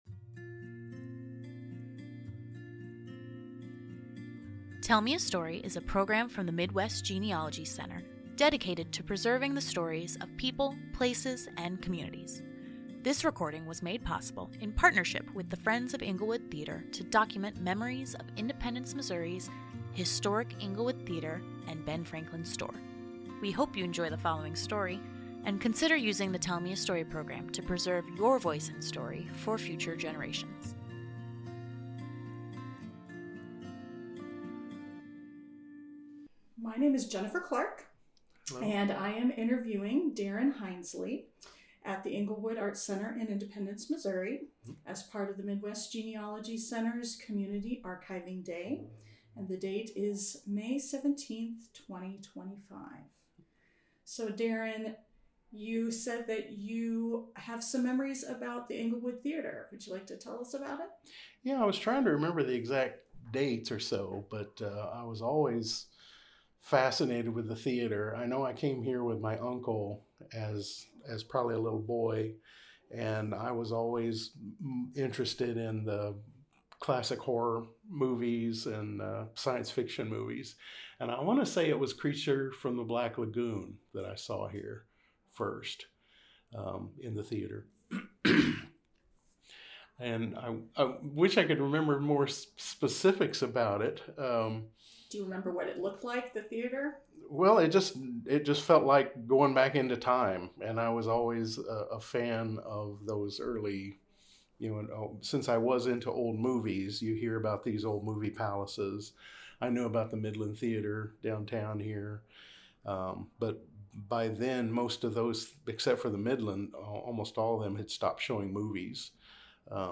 Englewood Theater Community Archiving Day - Oral Histories
interviewer
interviewee